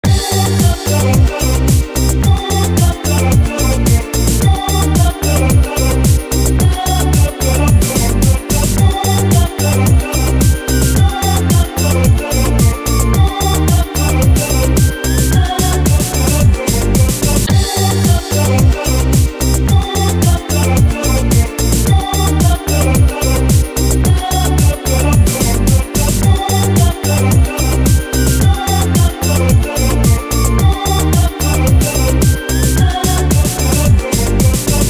• Качество: 256, Stereo
без слов
инструментальные
Инструментальный проигрыш